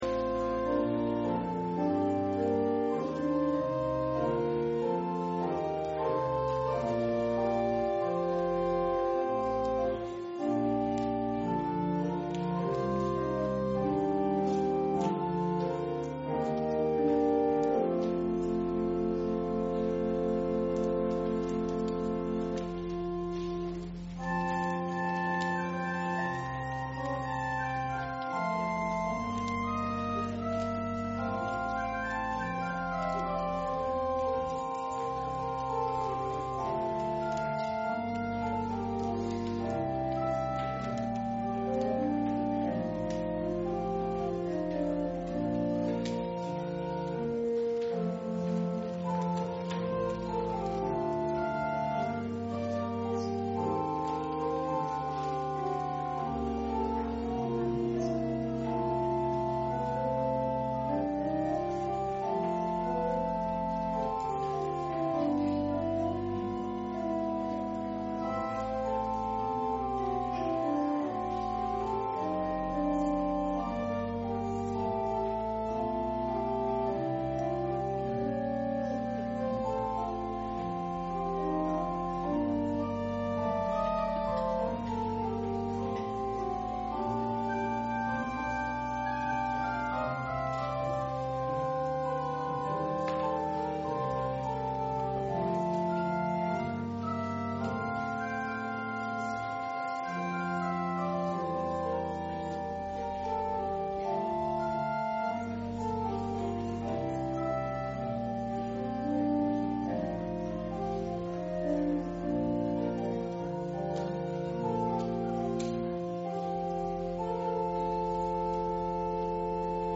Public Reading of Holy Scripture
Service Type: Sunday Morning